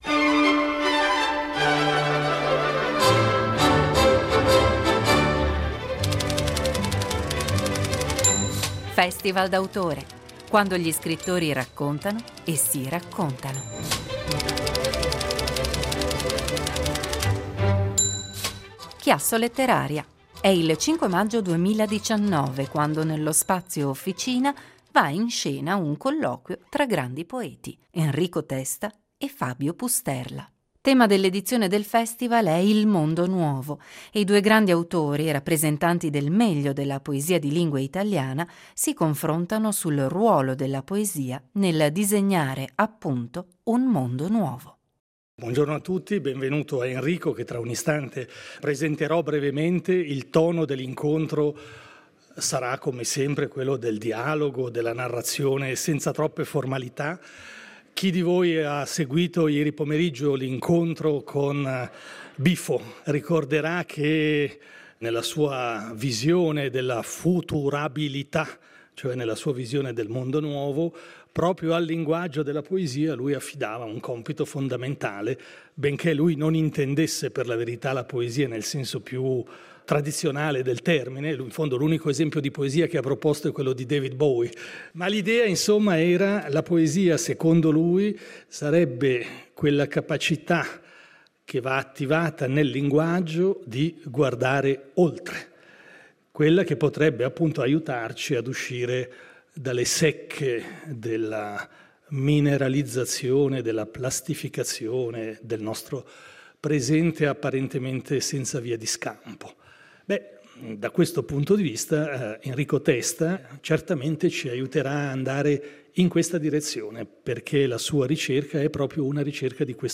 Il meglio dai Festival letterari della regione